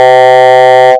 A320-family/Sounds/Cockpit/c-chord.wav at 6f75600c65943d581ac3efc15b19c3f116a39199
c-chord.wav